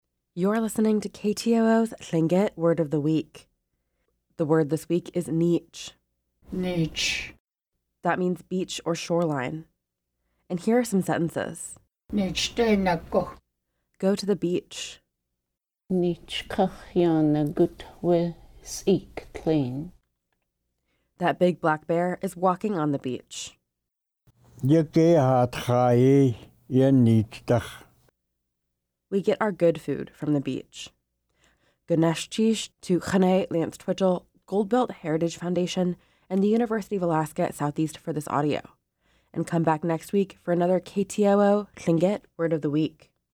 Each week, we feature a Lingít word voiced by master speakers.